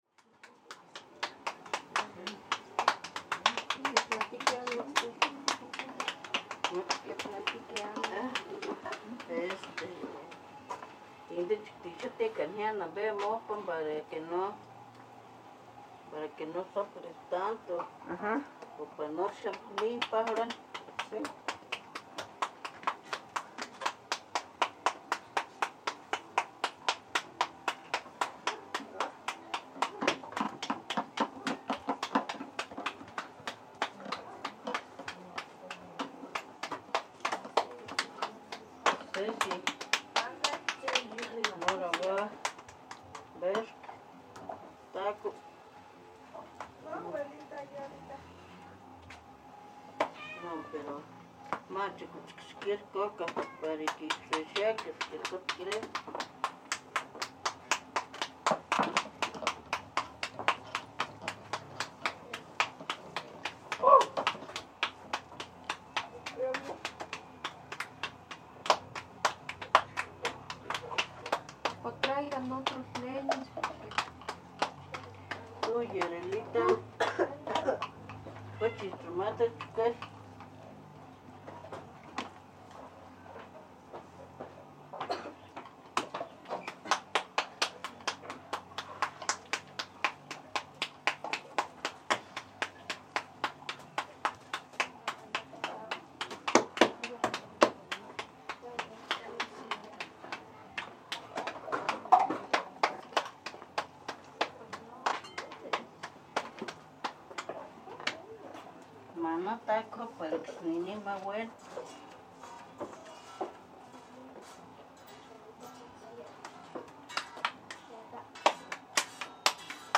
Traditional home made tortillas process. Stereo 48kHz 24bit.